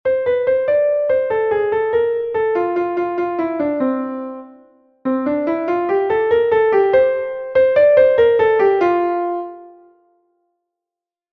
Allegro_assai.mp3